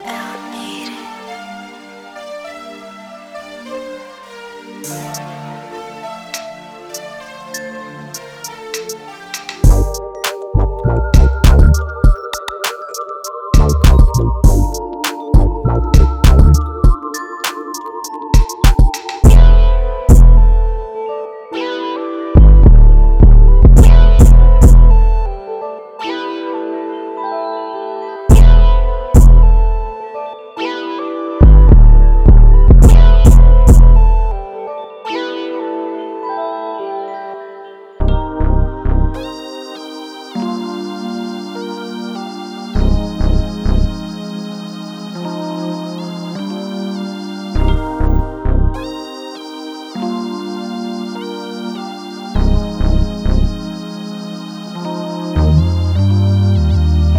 10 samples inspired by West Coast music.
• Hard 808s
• Funky Guitar
• Spacious Synthesizer
• Deep Bass
• Melodic Keys
• Percussion